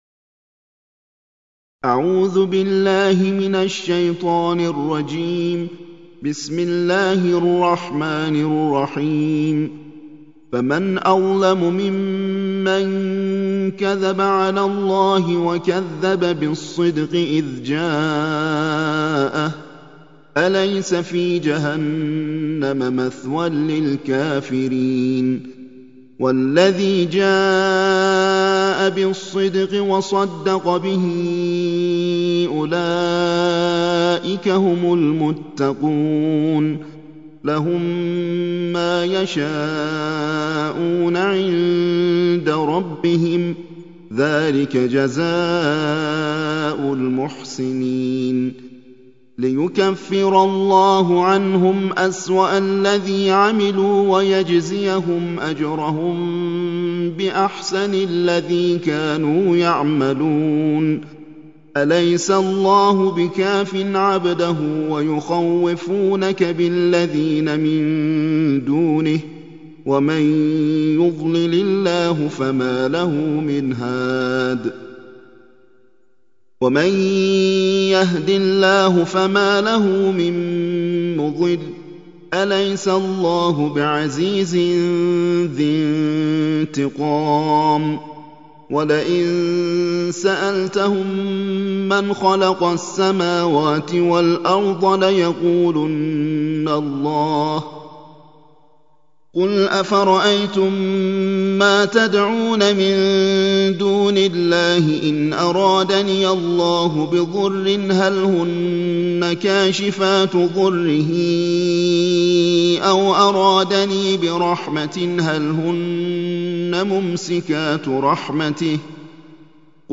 ترتيل القرآن الكريم